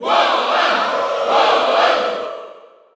Category: Wolf (SSBU) Category: Crowd cheers (SSBU) You cannot overwrite this file.
Wolf_Cheer_French_SSBU.ogg